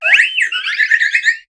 Cri de Floette dans Pokémon HOME.